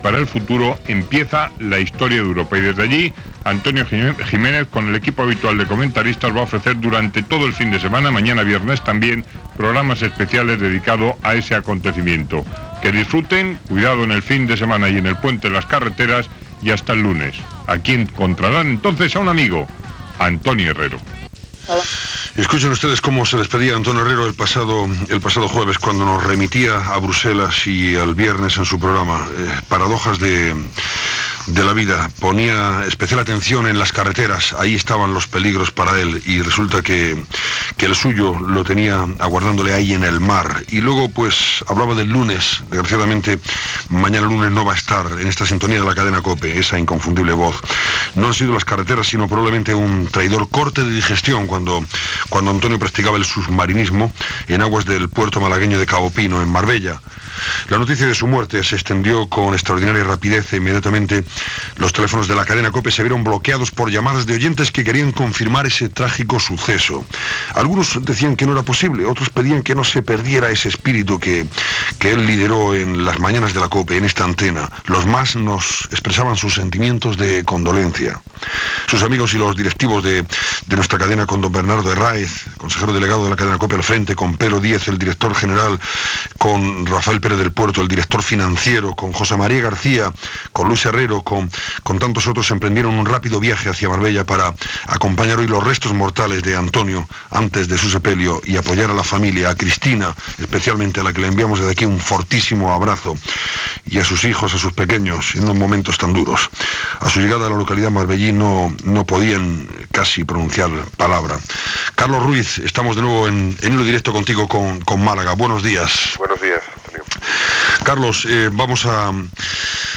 Inici del programa amb un fragment de l'últim espai fet per ell, connexió amb el tanatori i fragment del seu primer espai a la COPE. Gènere radiofònic Informatiu